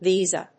音節vi・sa 発音記号・読み方
/víːzə(米国英語), ˈvi:zʌ(英国英語)/